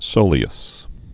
(sōlē-əs)